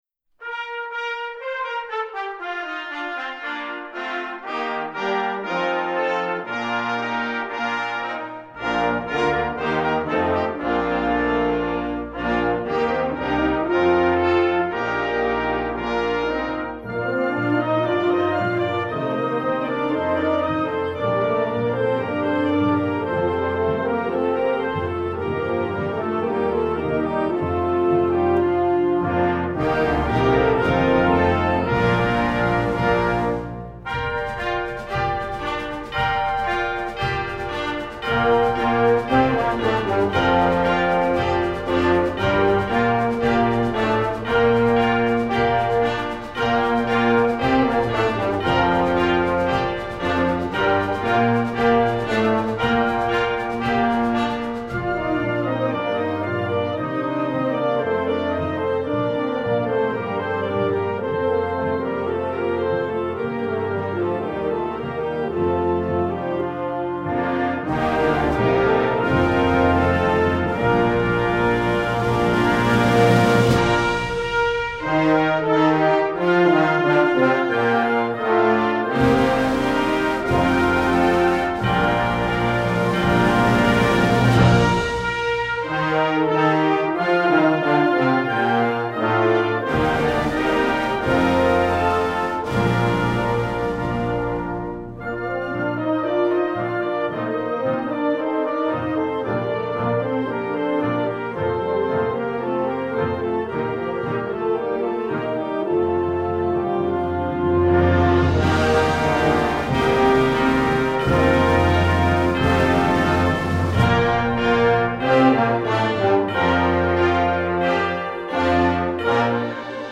classical, instructional, choral